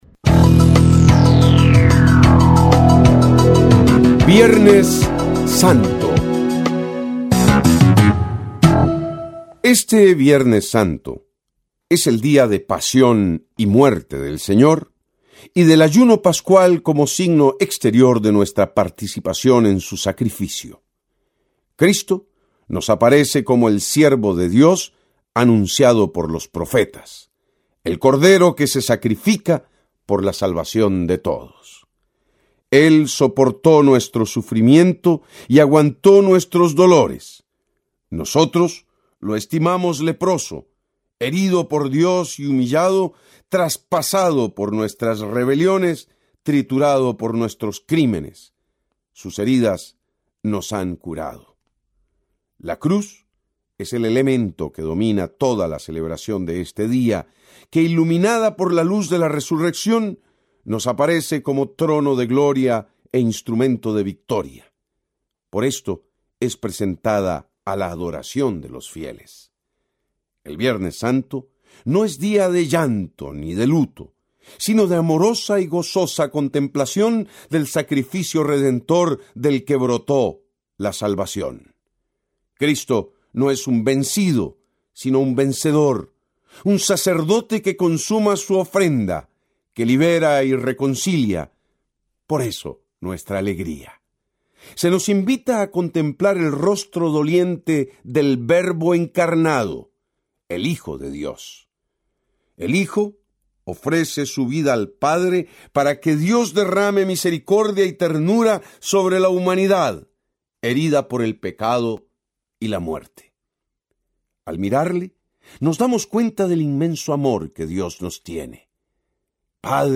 Comentarista Invitado